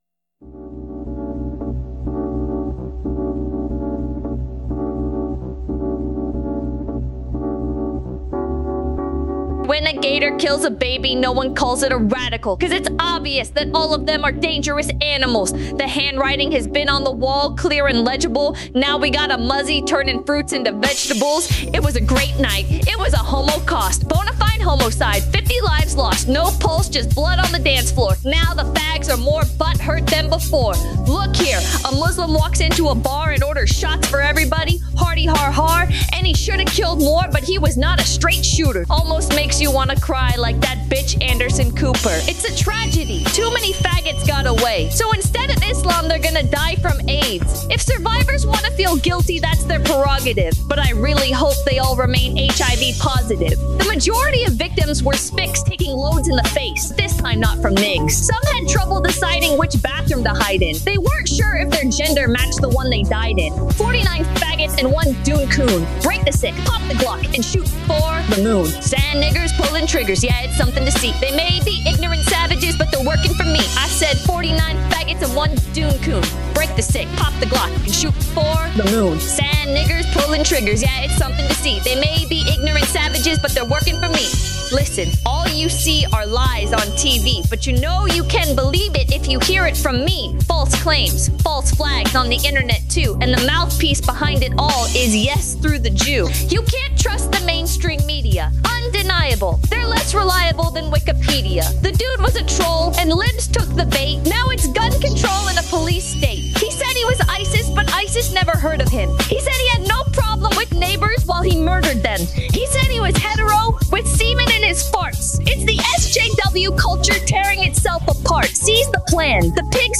sing it (AI).